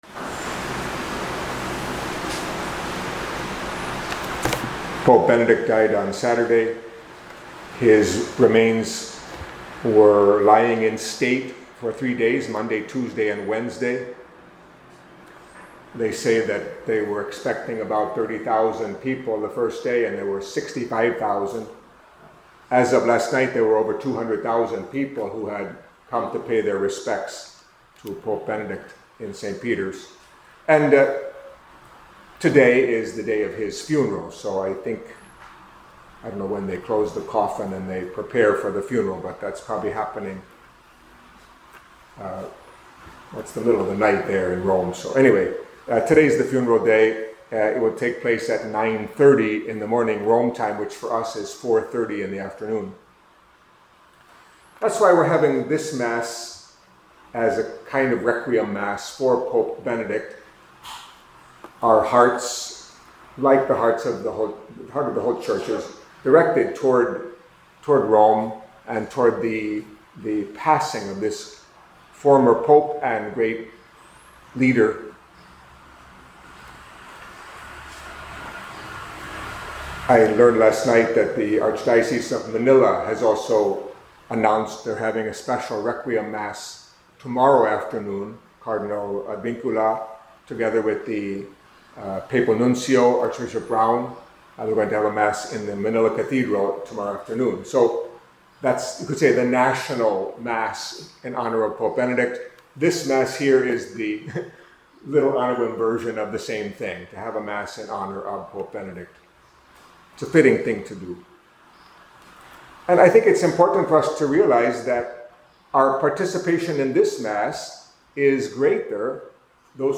Catholic Mass homily for a Requiem Mass for Pope Benedict